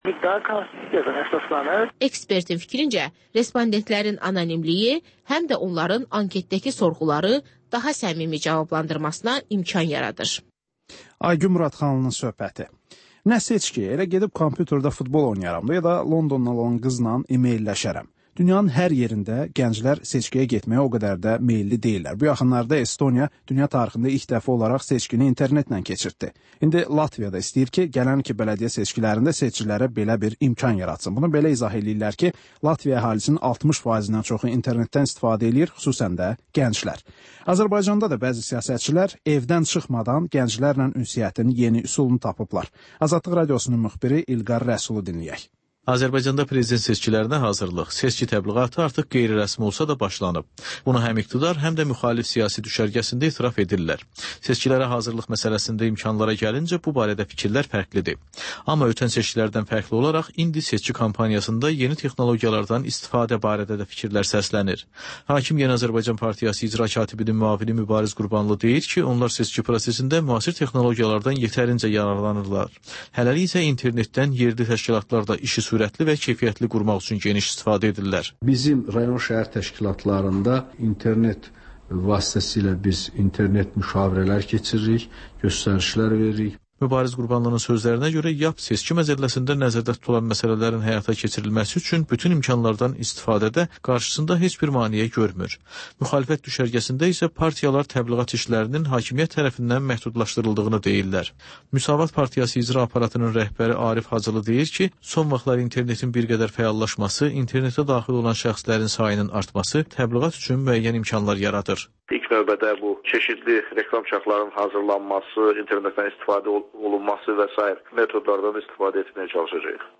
Hadisələrin təhlili, müsahibələr, xüsusi verilişlər